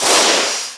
fire_countermeasure.wav